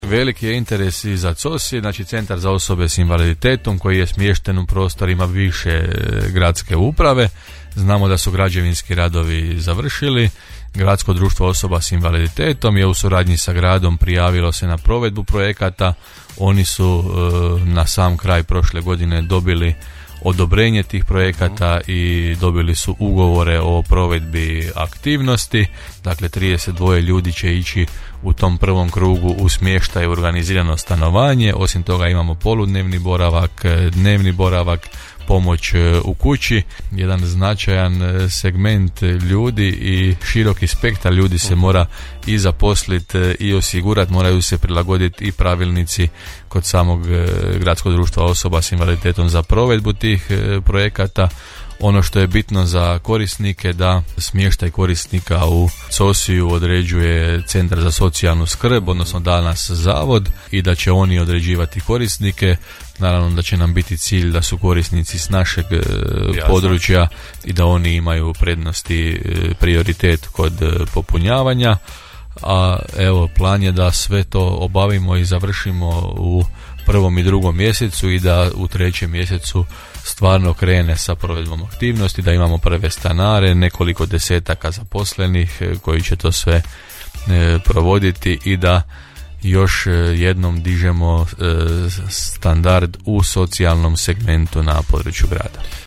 U emisiji Gradske teme Podravskog radija gost je bio gradonačelnik Đurđevca Hrvoje Janči, koji je među ostalim potvrdio uskoro novi program zapošljavanja gerontodomaćica te nastavak radova u gradu i prigradskim naseljima: